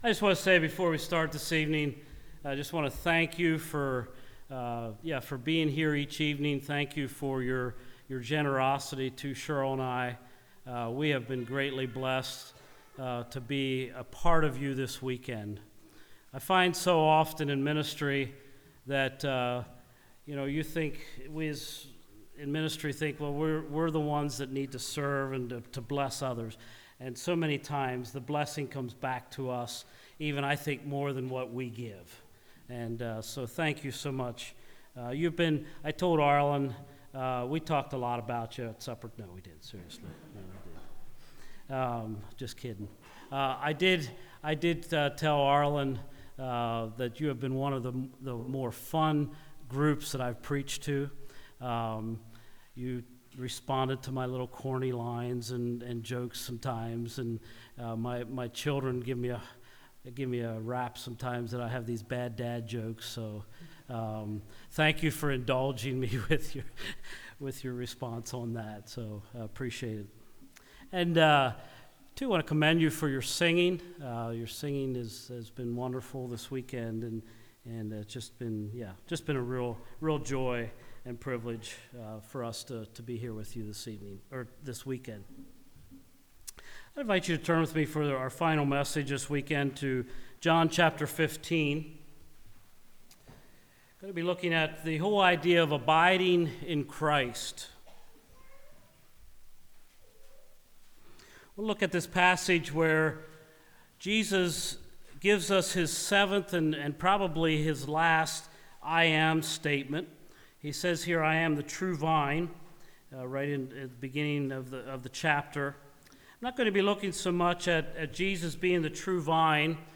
Service Type: Revivals